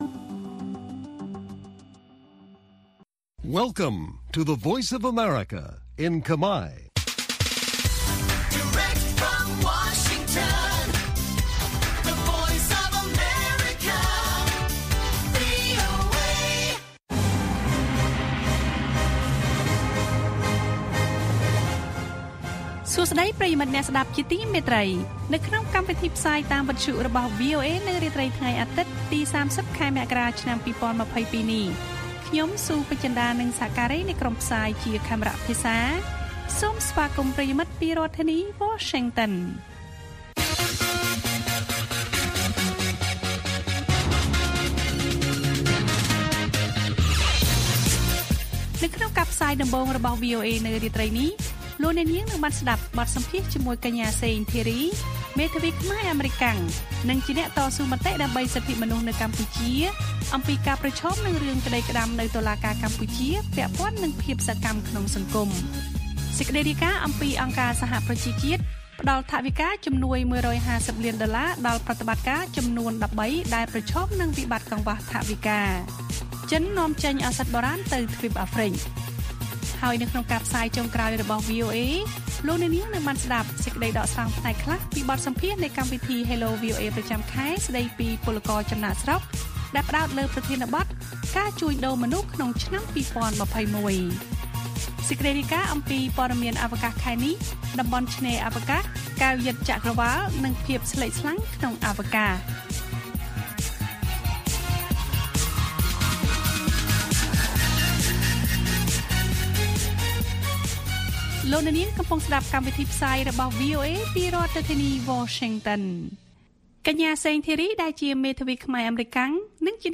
ព័ត៌មានអំពីកម្ពុជាមាន បទសម្ភាសន៍៖ អ្នកច្បាប់ខ្មែរអាមេរិកាំងតស៊ូ ទាមទារសិទ្ធិមនុស្សនៅកម្ពុជា។ សេចក្តីដកស្រង់នៃកម្មវិធី Hello VOA ដែលពិភាក្សាអំពី«ការជួញដូរមនុស្សក្នុងឆ្នាំ២០២១»។